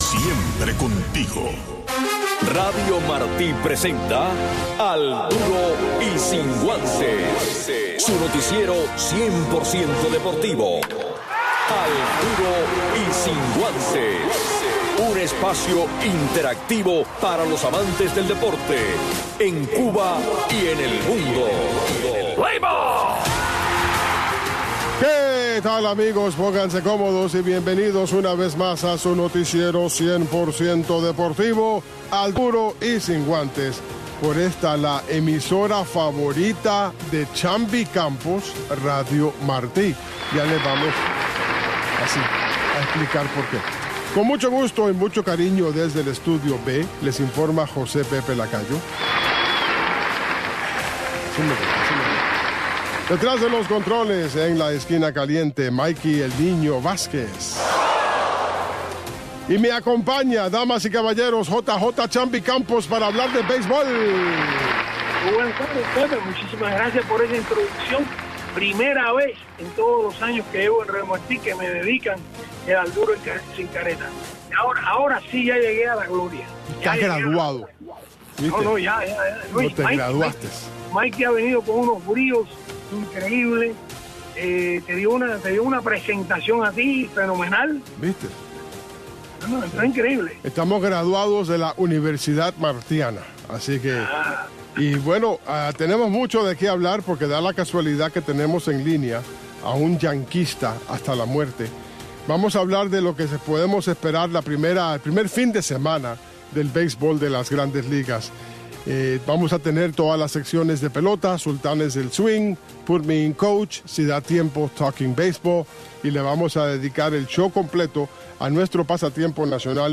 Un resumen deportivo